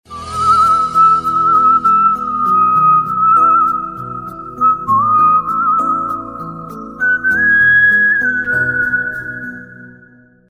• Качество: 128, Stereo
свист
короткие